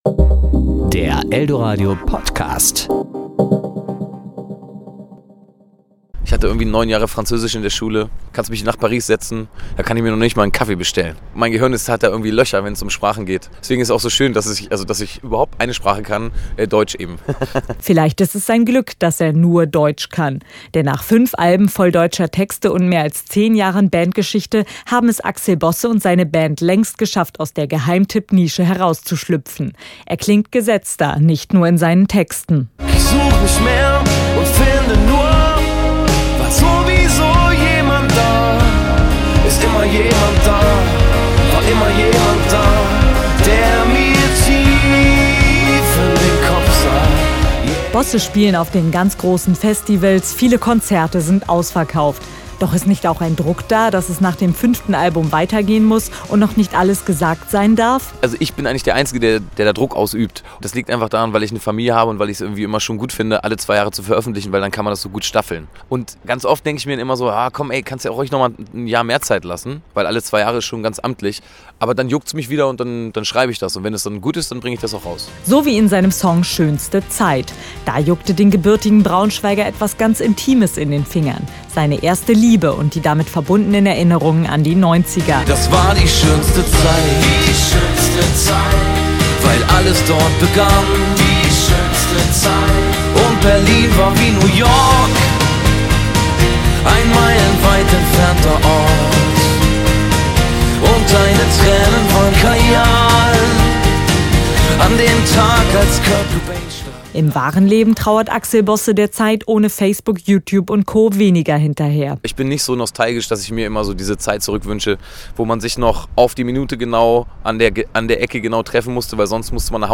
Axel Bosse im Interview